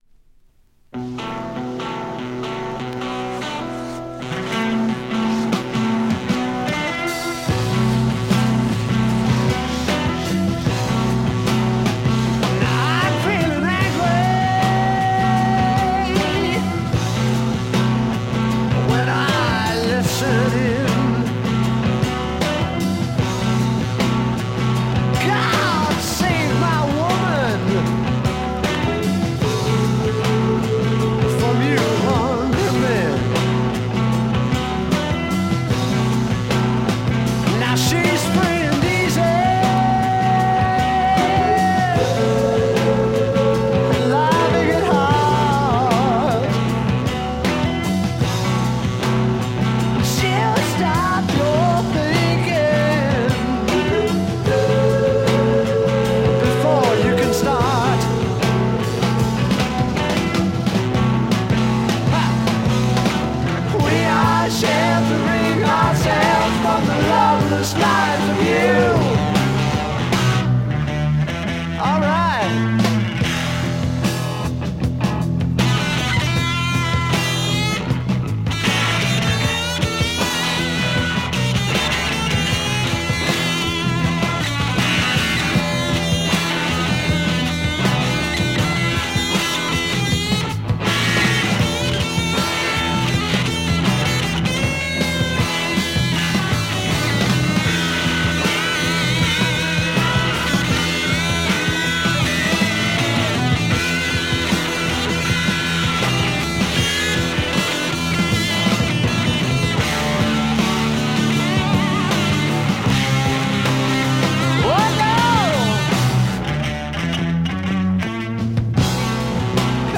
Killer US Psych rock